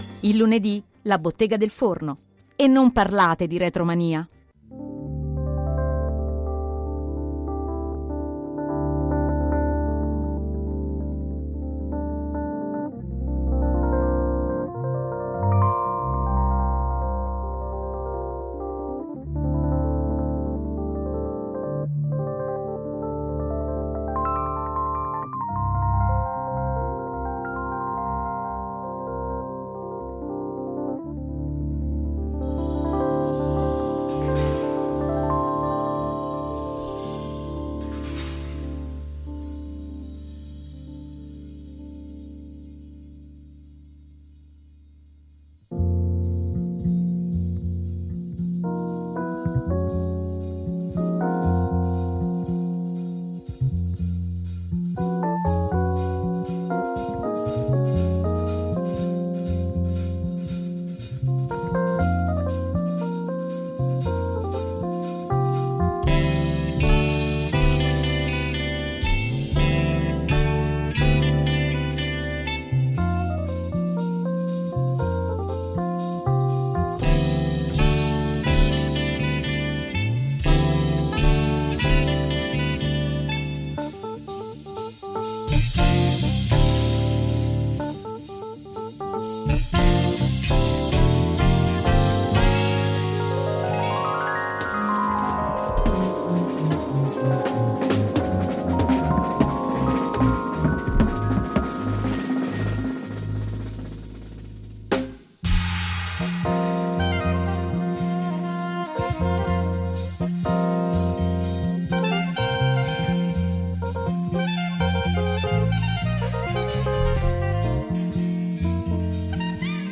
Intervista a Giovanni Tommaso